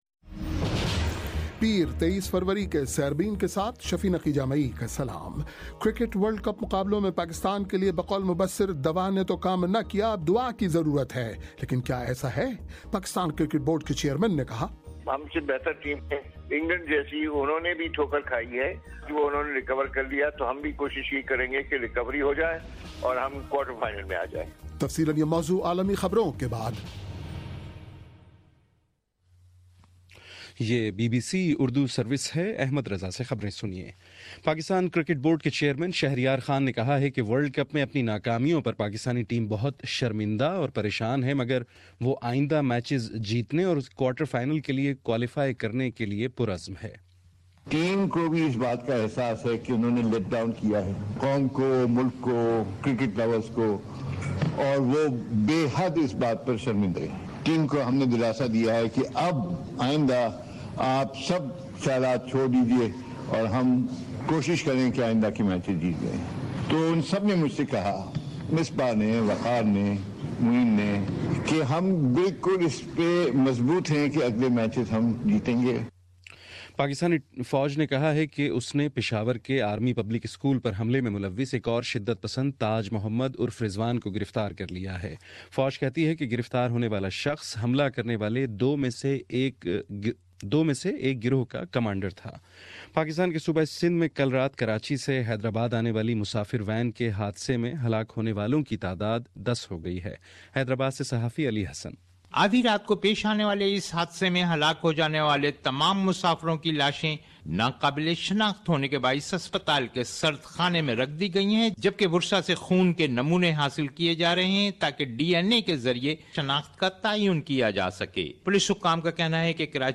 پیر23 فروری کا سیربین ریڈیو پروگرام